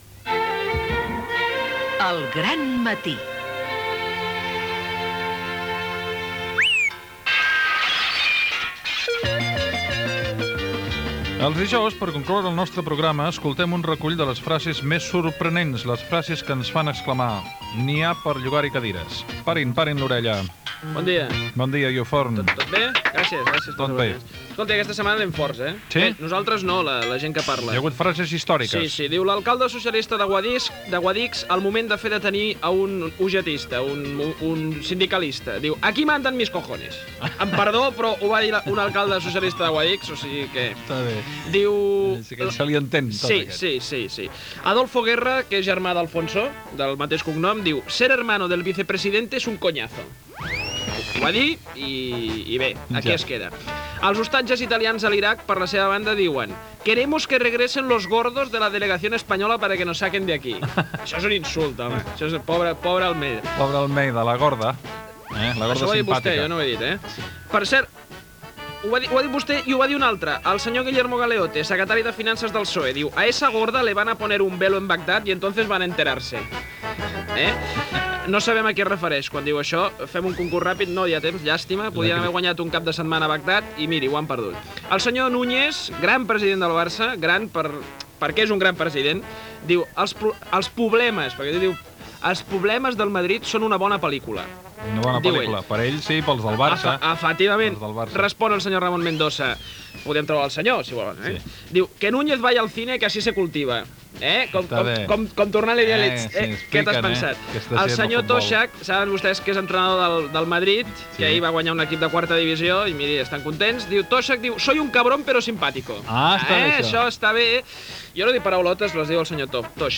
Indicatiu del programa, secció "N'hi ha per llogar-hi cadides": frases curioses recollides de declaracions de diverses persones. Comiat i equip del programa
Info-entreteniment
FM